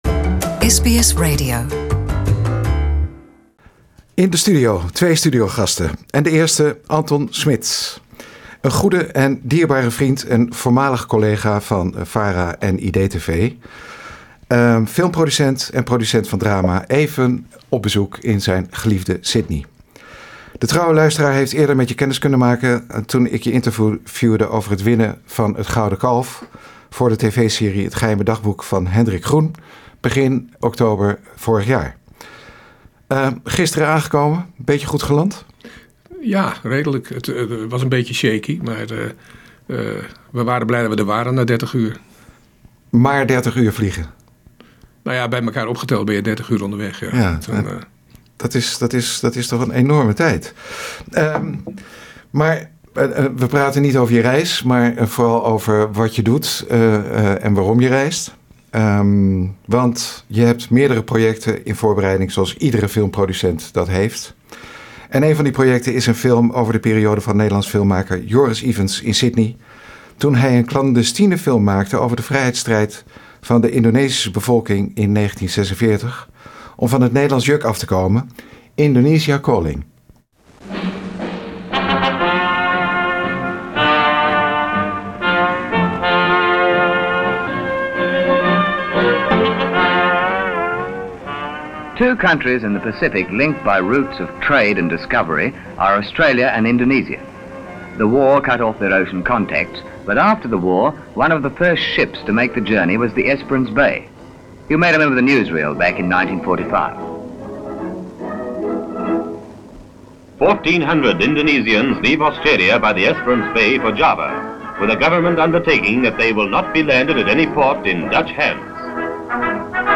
is te gast in de Sydney studio